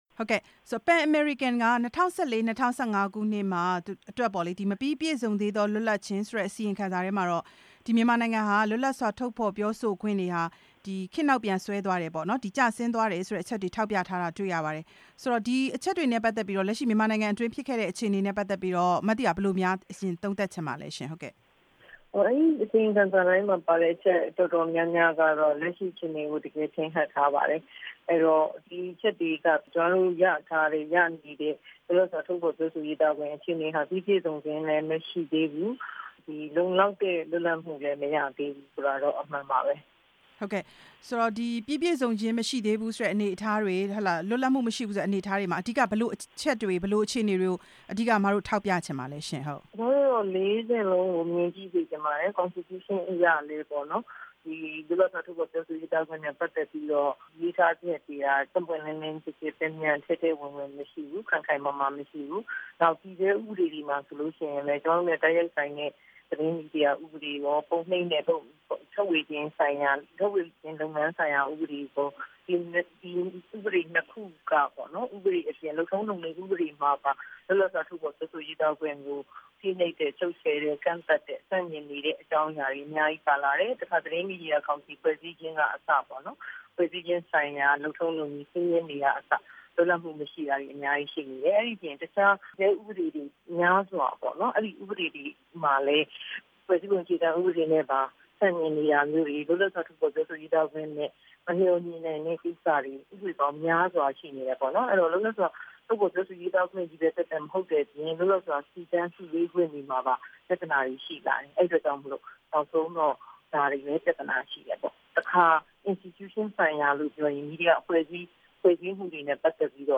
စာရေးဆရာမ မသီတာ (စမ်းချောင်း)နဲ့ မေးမြန်းချက်